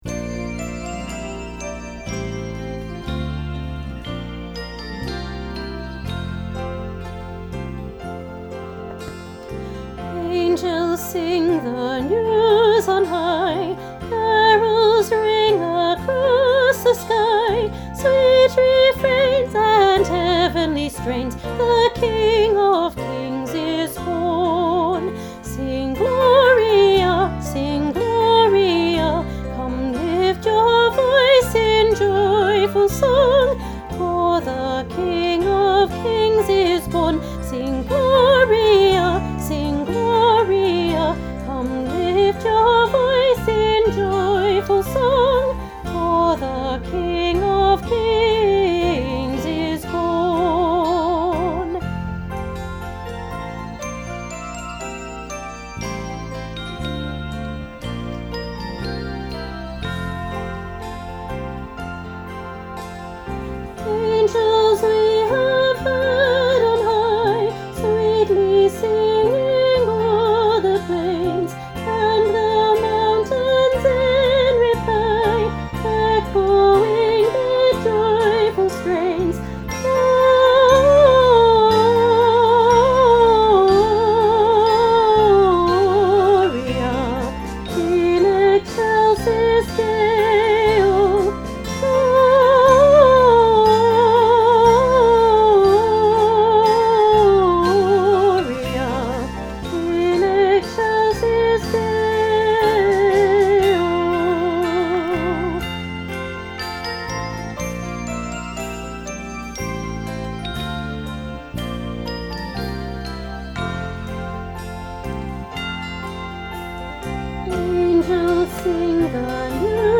Junior-Choir-Carol-of-the-Angels-Part-1.mp3